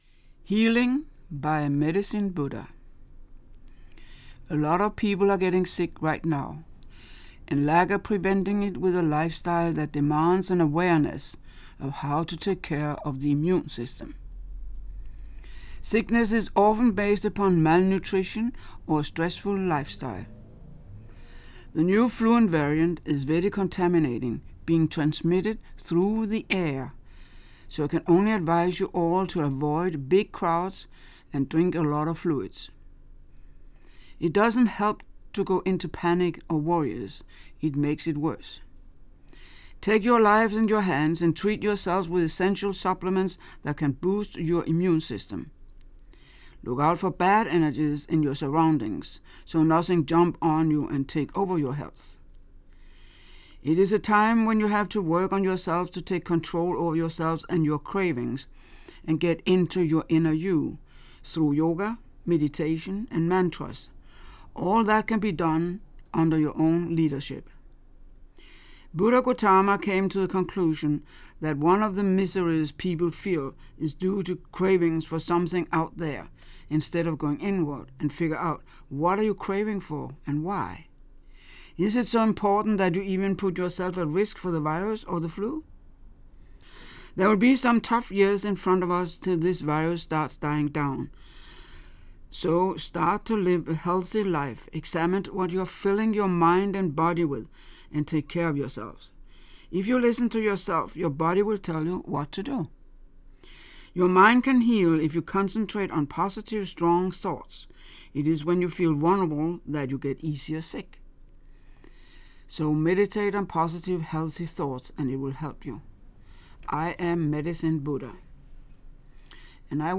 Amazing singing along with the Natural World just doing what they do as the world turns, year after year.